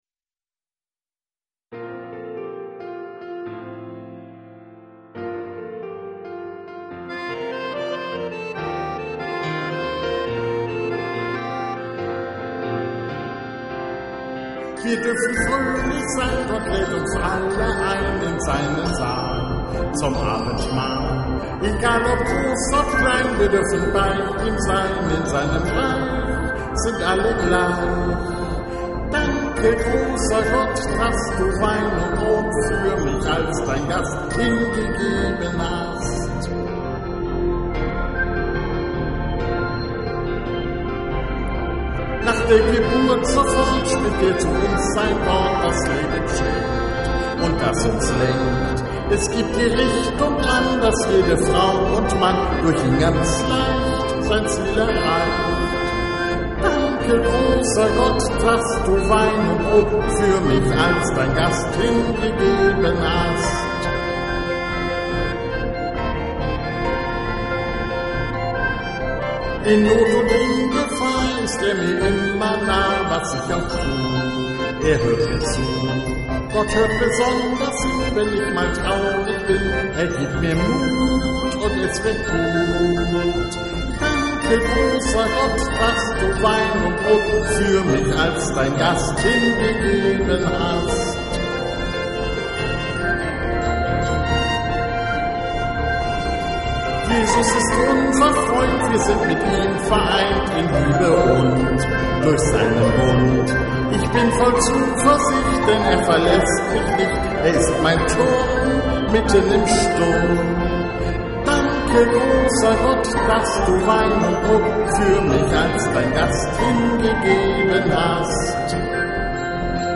Wir-dürfen-fröhlich-sein-Gesang.mp3